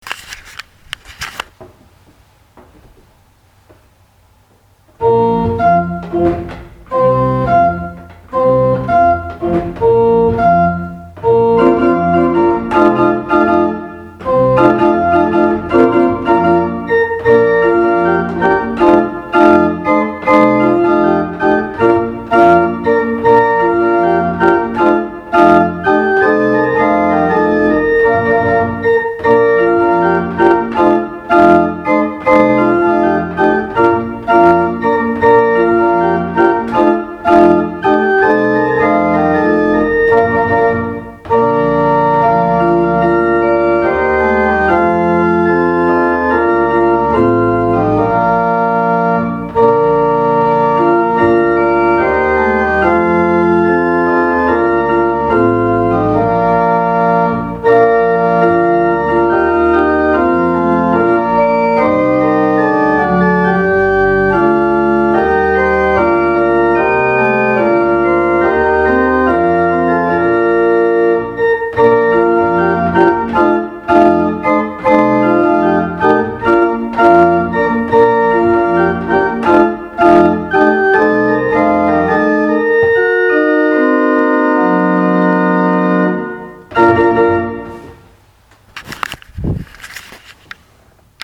auf der Orgel in der Marien-Kirche zu Breinum